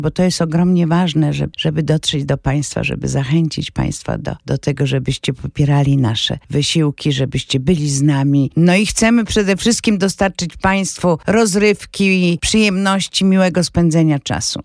W studio Radio Deon Chicago gość